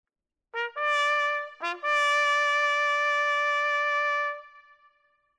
Bugle Calls